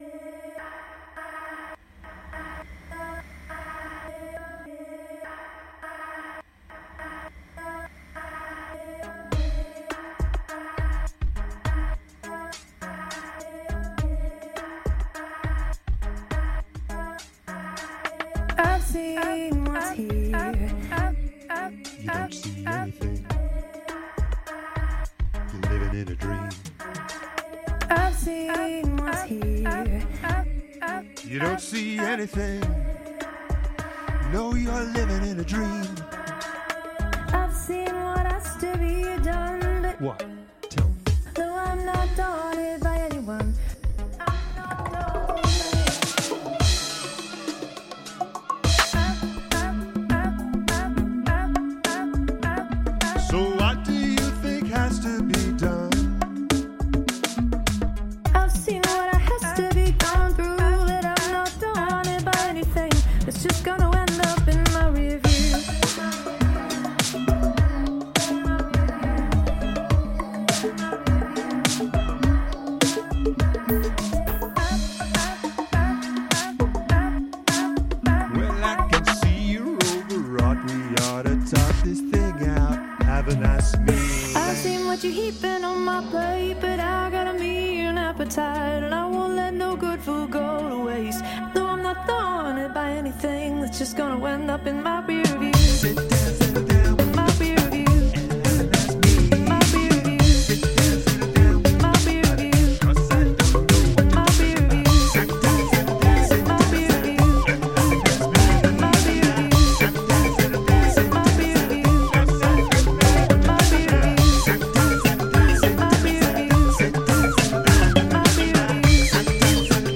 This is fun, nicely glitchy electronic pop.